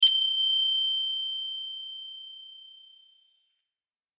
風鈴4.mp3